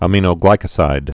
(ə-mēnō-glīkə-sīd, ămə-)